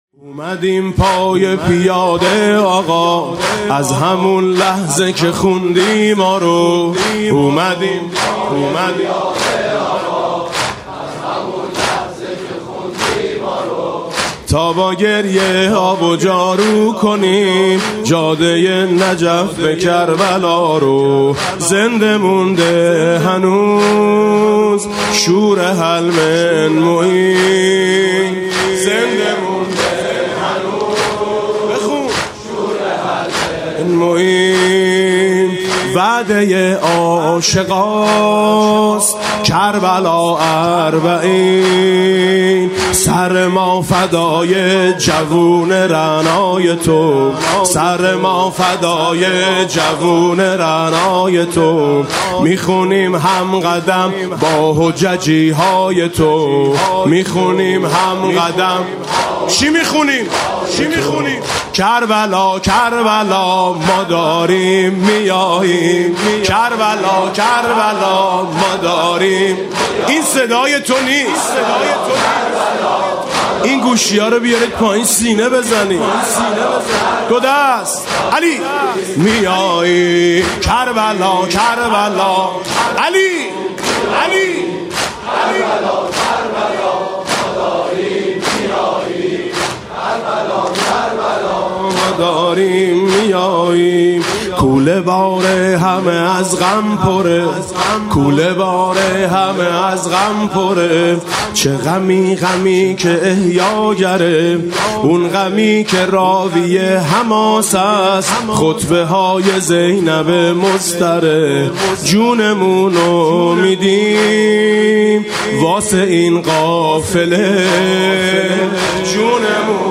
مسیر پیاده روی نجف تا کربلا [عمود ۹۰۹]
مناسبت: ایام پیاده روی اربعین حسینی
با نوای: حاج میثم مطیعی
قدم قدم موکبا رو می‌گردم (زمینه اربعینی)